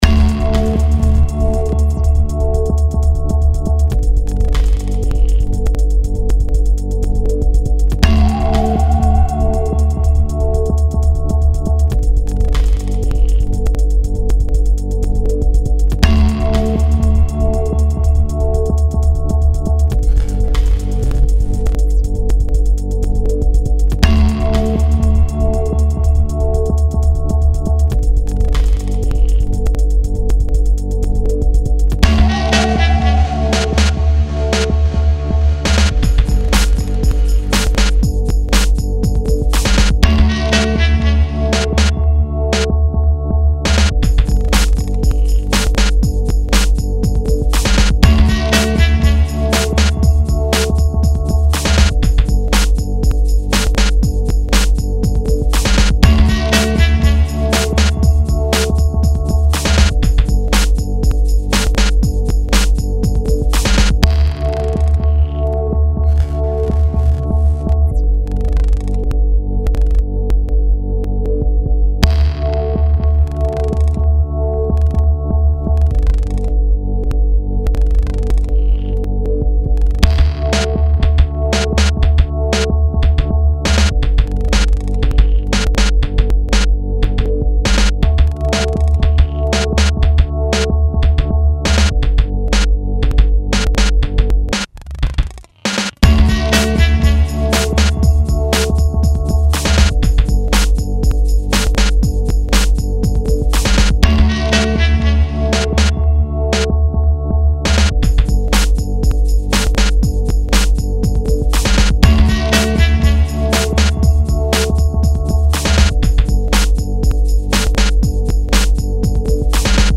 Guitares, samples, claviers, programmations, bricolages.
à évolué vers un son plus électronique